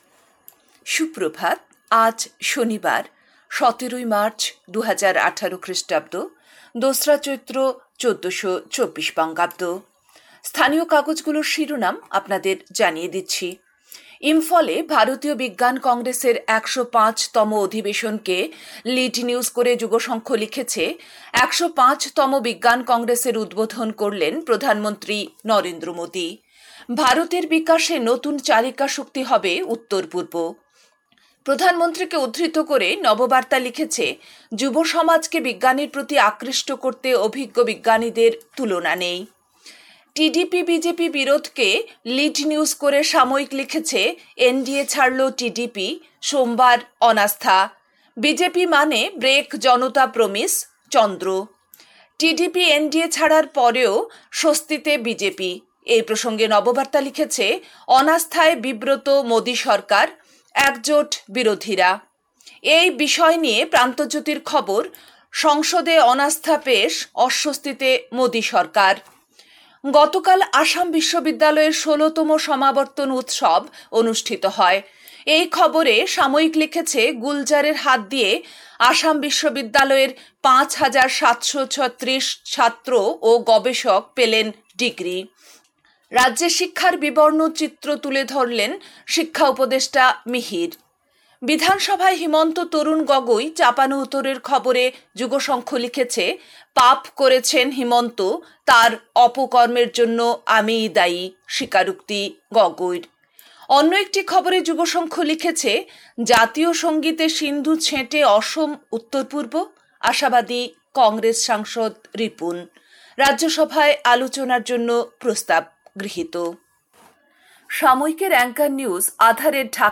A quick bulletin with all top news.